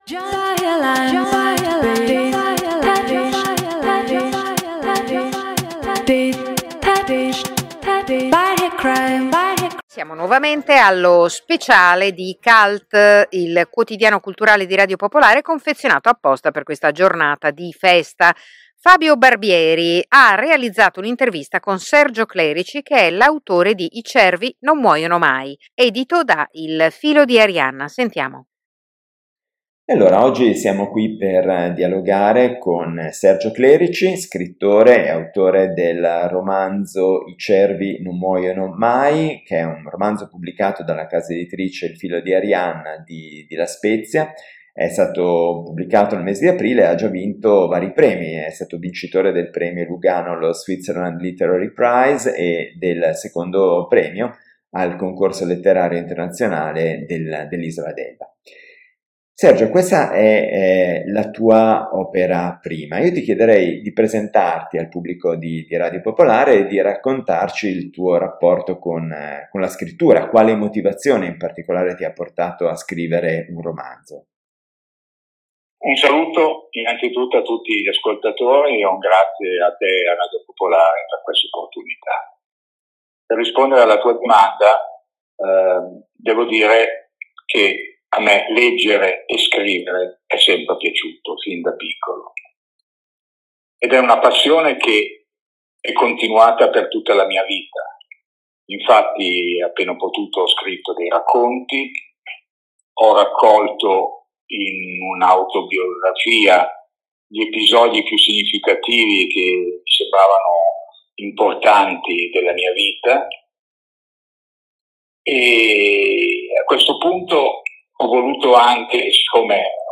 Intervista su Radio Popolare, Milano 11 Novembre 2024
Intervista-Radio-Popolare-MP3.mp3